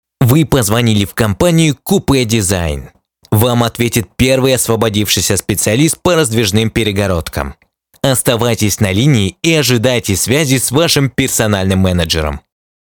Пример звучания голоса
Муж, Другая
Звуковая карта focusrite solo 3rd, микрофон se electronics x1 s